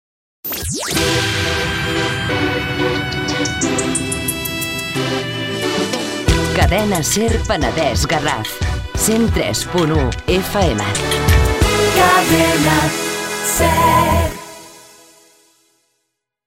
Identificació i freqüència
Banda FM